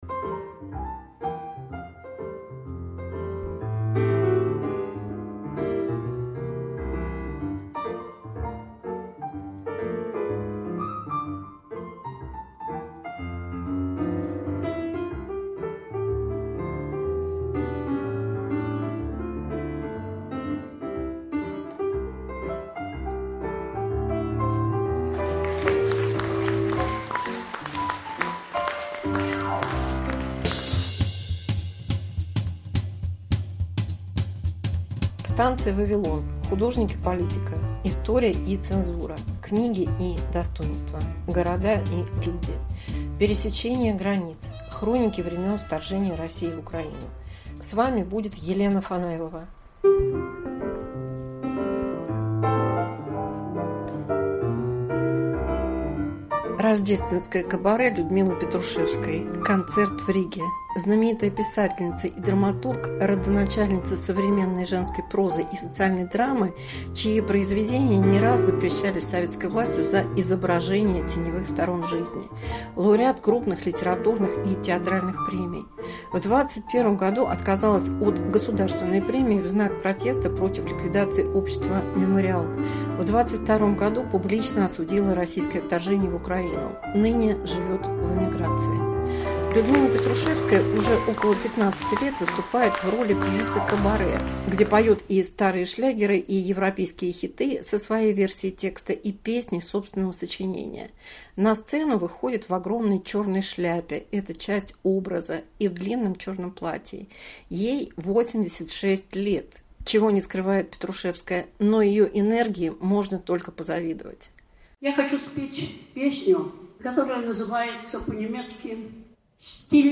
Писательница поёт